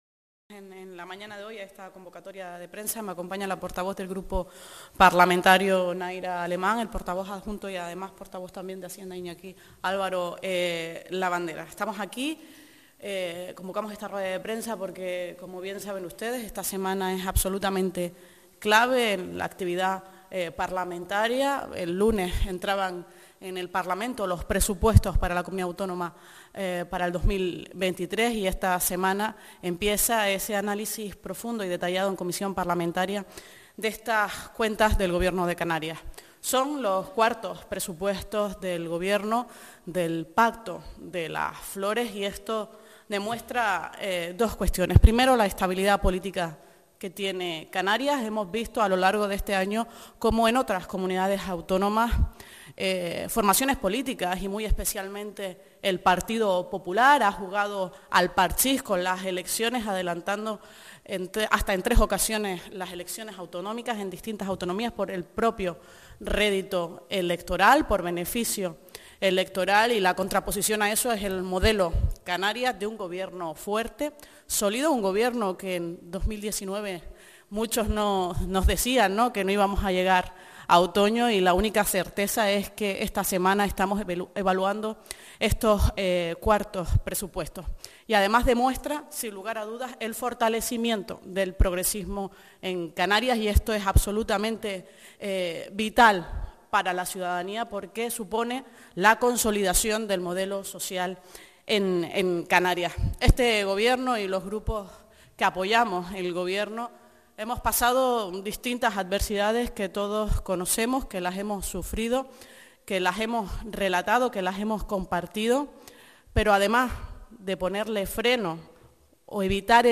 Rueda de prensa del GP Socialista Canario sobre Proyecto de ley de Presupuestos de Canarias 2023 - 11:00